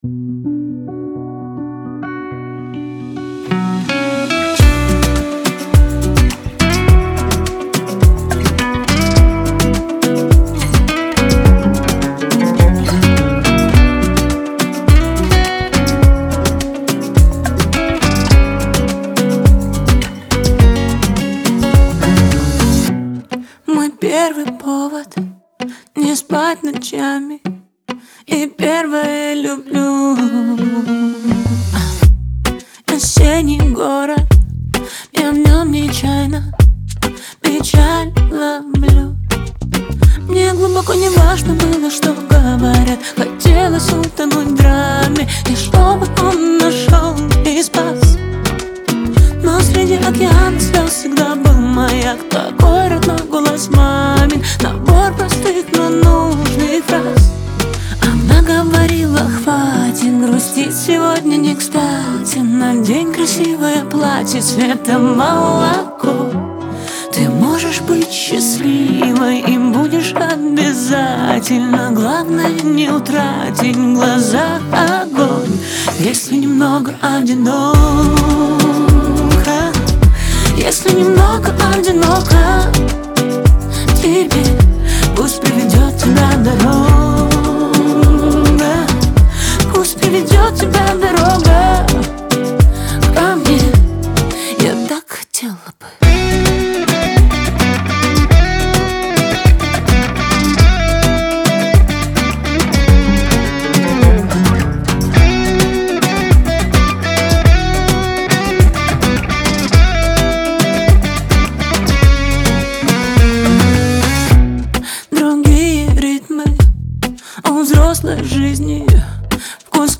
энергичная и зажигательная песня